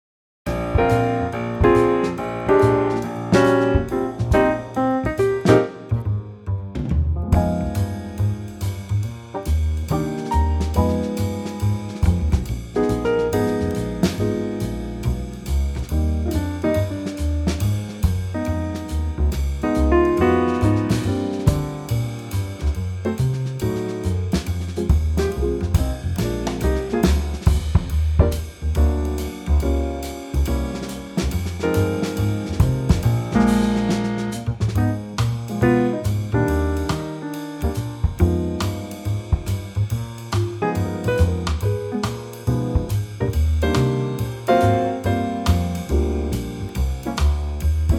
key - Eb - vocal range - Bb to Eb
Superb Trio arrangement